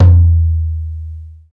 TOM XTOMLO06.wav